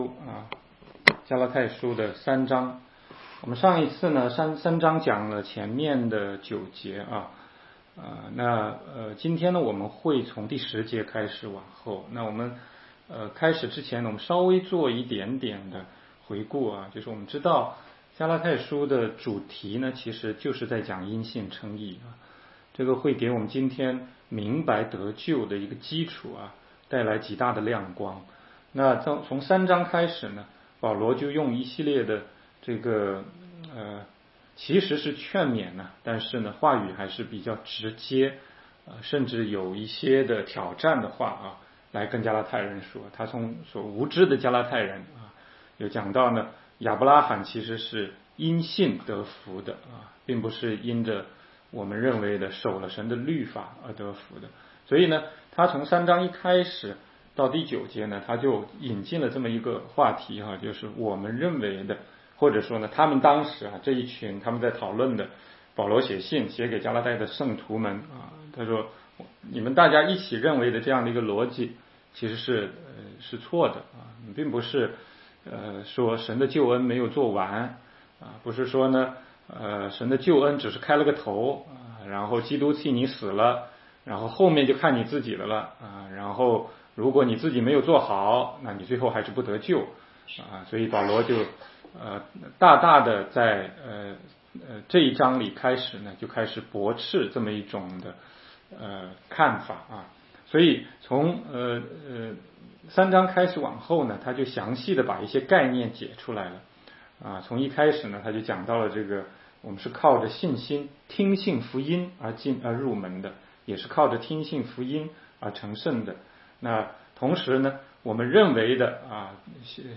16街讲道录音 - 加拉太书3章10-14节：义人必因信得生